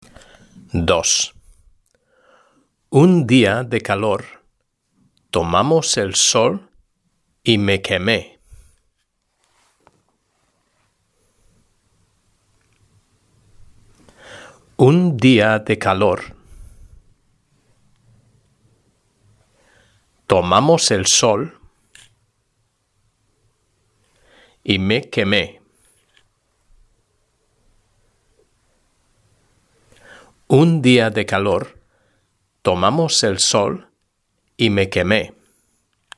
3.1 Los viajes y el turismo: Dictado #1 (H) – SOLUCIÓN